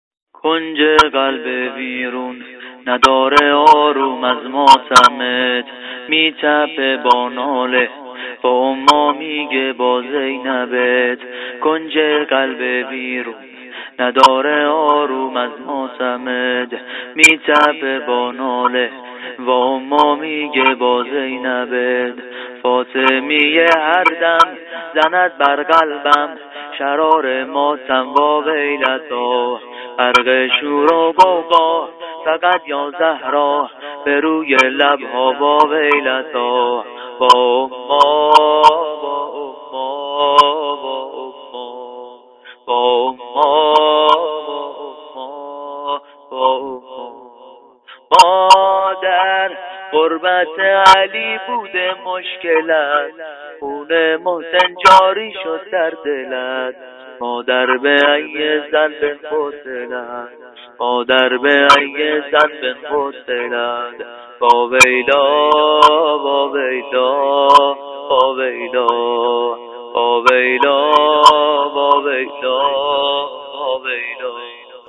مناجات با حضرت زهرا (س)
زمینه - شور